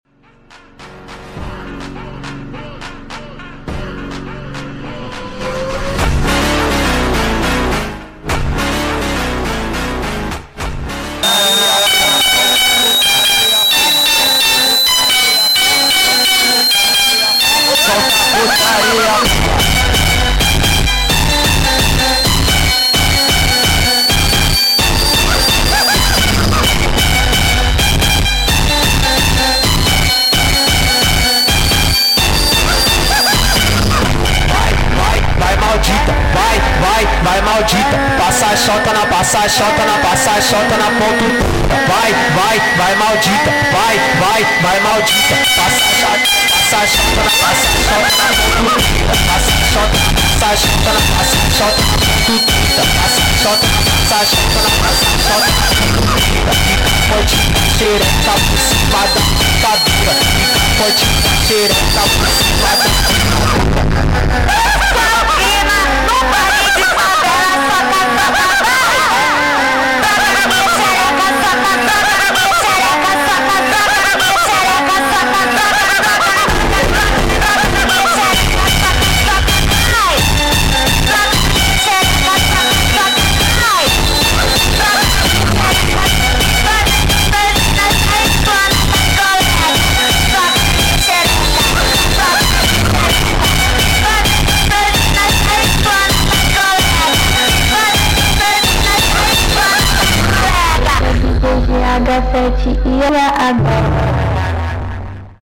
Be careful with your ears👍🔊⚠